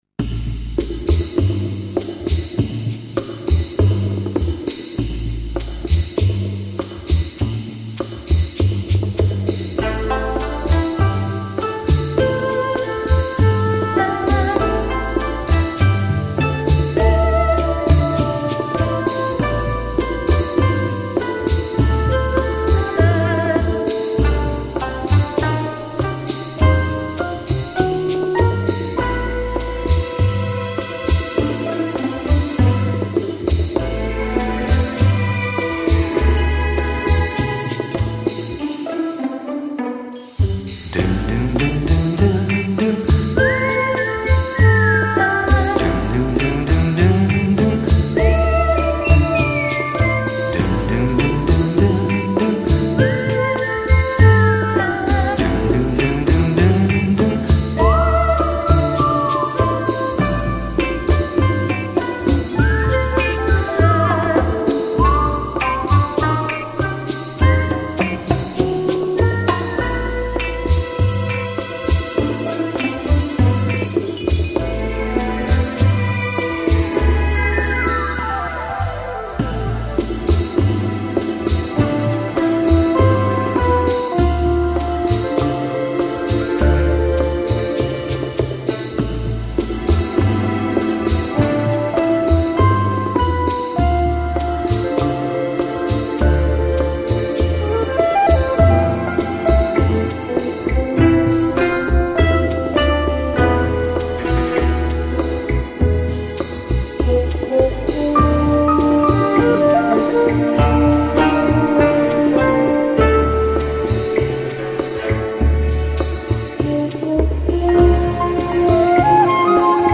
把法国的浪漫优雅带到如此有中国特色的音乐里，制成这张精美的OST，国内没有多少电影原声能达到这个水平。